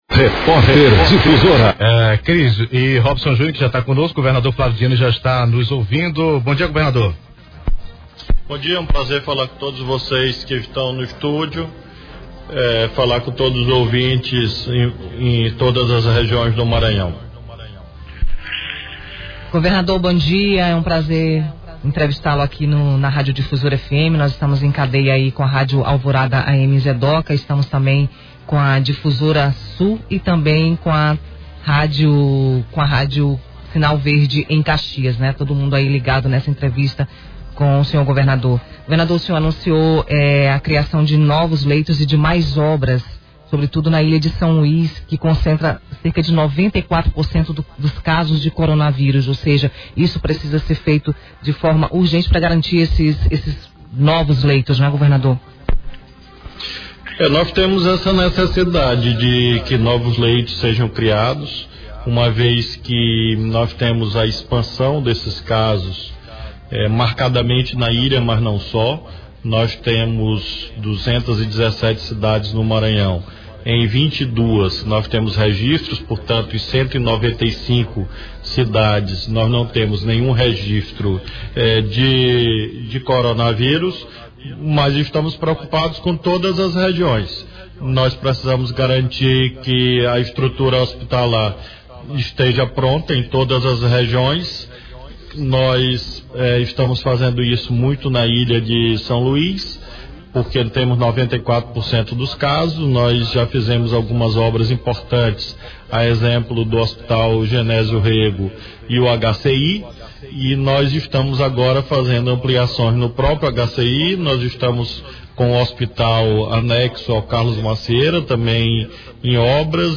Confira a íntegra da entrevista do governador Flávio Dino à Difusora nesta segunda-feira
Entrevista-Flávio-Dino-rádio-Difusora.mp3